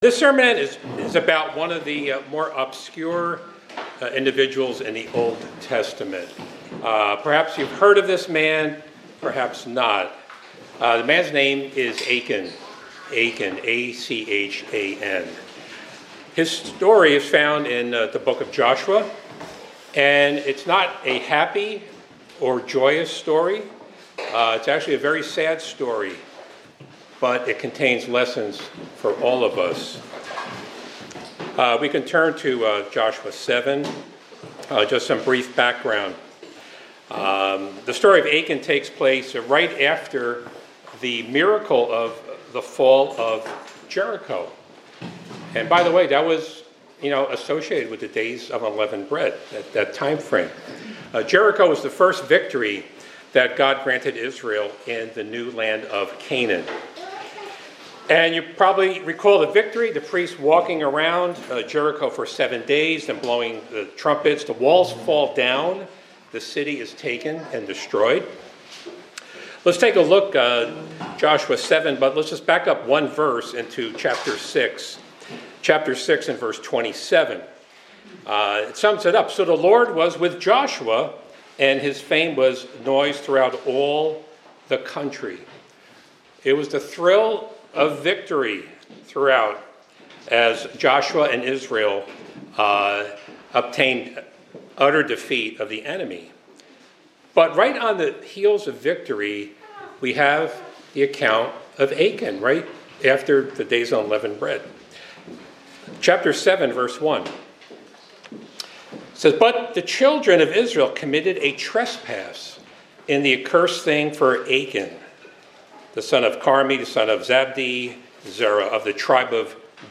The sermon emphasizes that God’s commands are serious, that individual sin can affect the whole body of believers, and that nothing is hidden from God.
Given in Hartford, CT